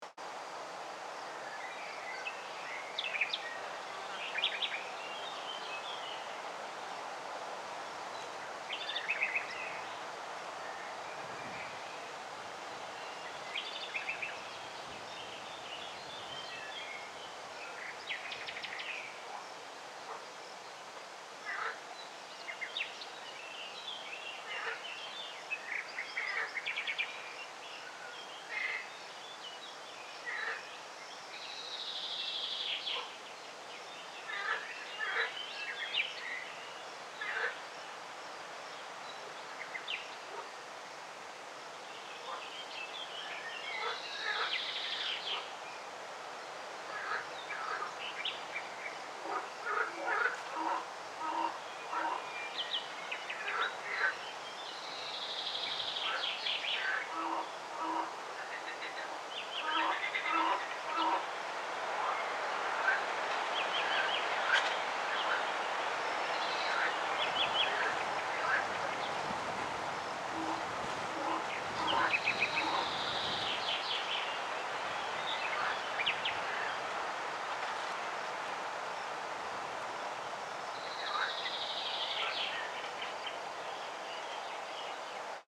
Download Swamp sound effect for free.
Swamp